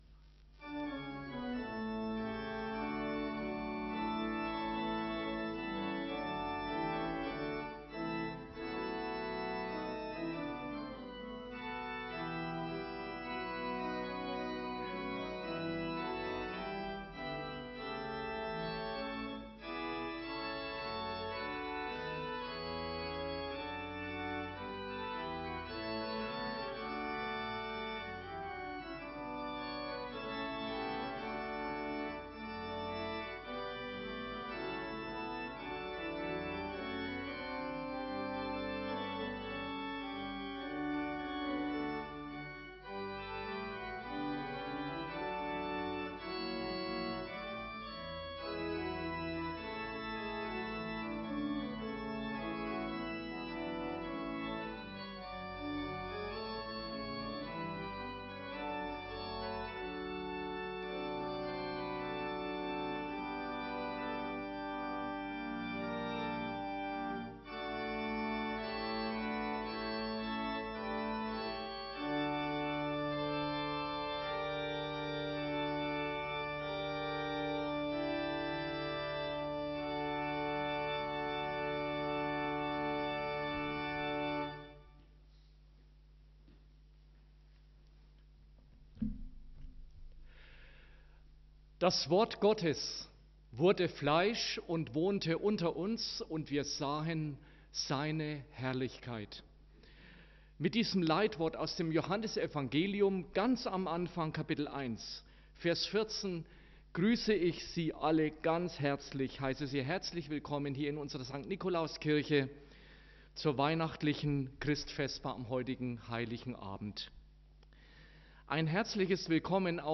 251224_Christvesper.mp3